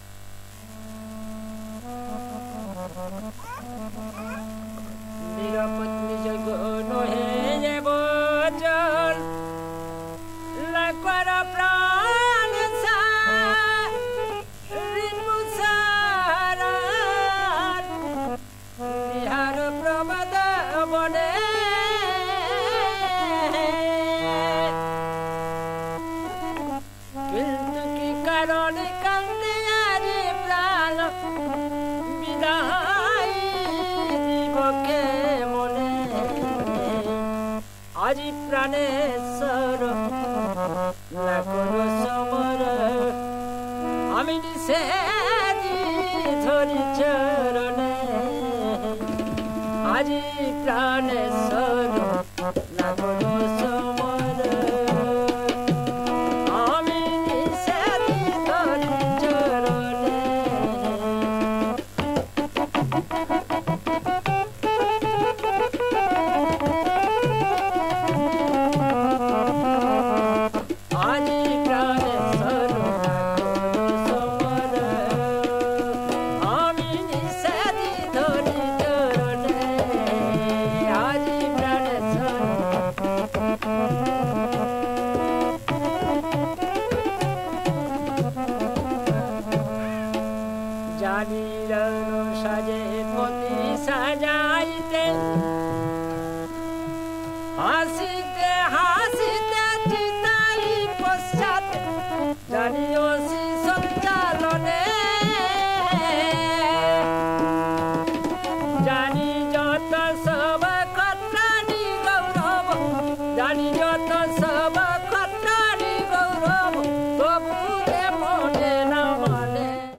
バングラディッシュと東インド、ベンガル地方の音源を収録したフィールド録音！
バングラディッシュと東インド、ベンガル地方の音源を収録したフィールド録音作品！
１弦楽器エクトラと打楽器ドゥギで彩りを添えた、見事な歌唱を堪能できますよ！
※レコードの試聴はノイズが入ります。